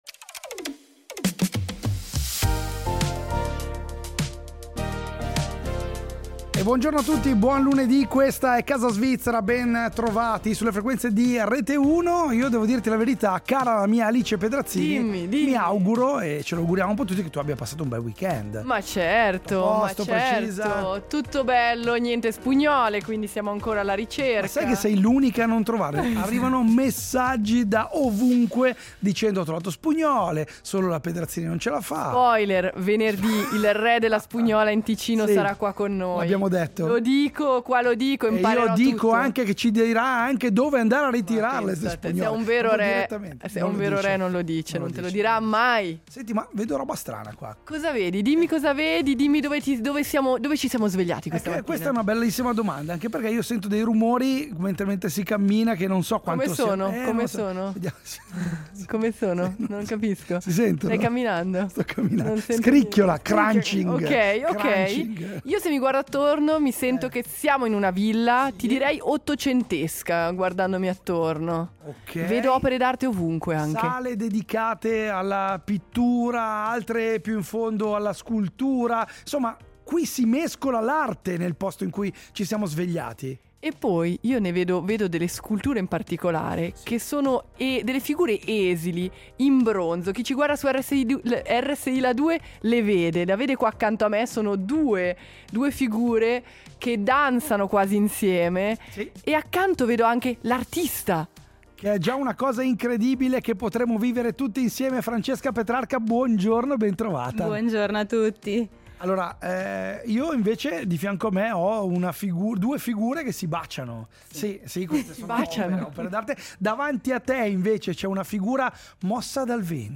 Sembra una villa elegante, con il parquet che scricchiola sotto i passi… ma non è silenzio da museo.
Siamo entrati a Villa Ciani, a Lugano, dentro YouNique (27-29 marzo): una fiera internazionale che sembra più una casa abitata da opere.